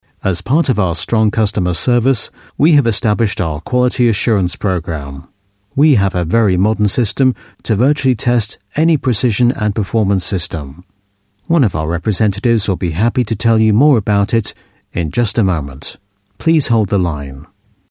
Native Speaker
Telefonansagen